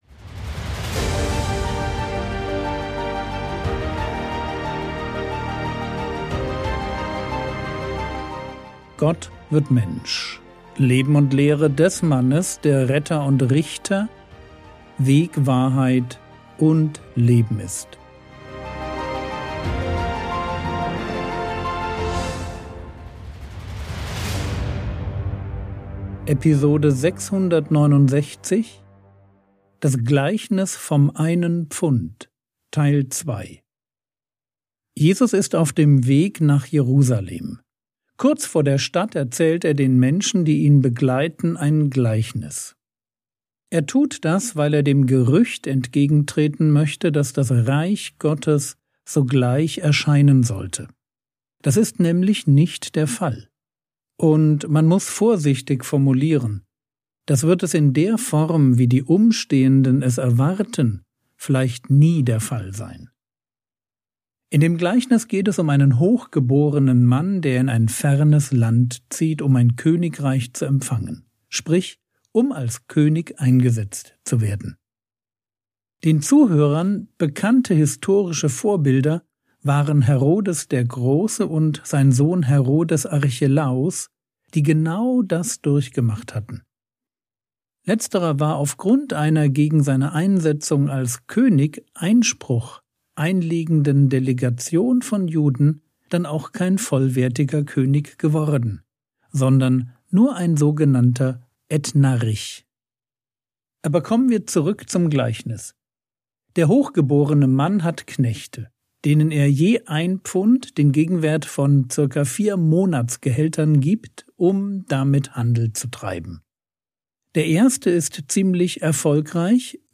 Episode 669 | Jesu Leben und Lehre ~ Frogwords Mini-Predigt Podcast